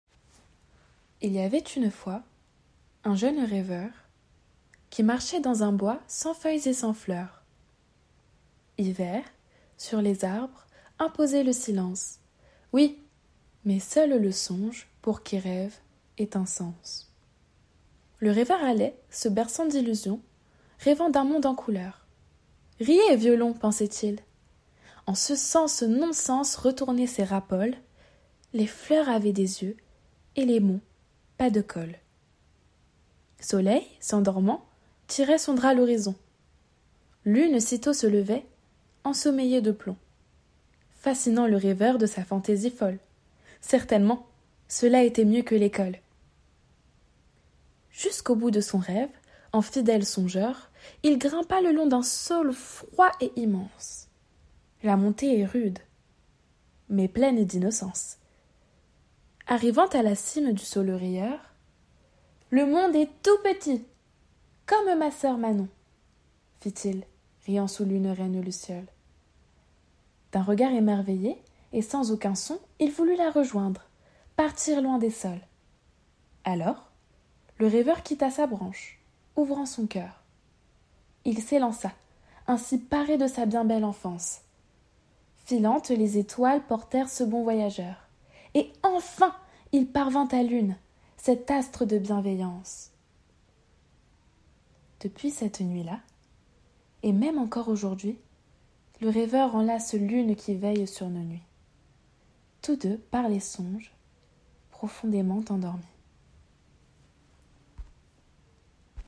Lecture d’un comte - projet de comte animé - Le rêveur et la Lune
- Mezzo-soprano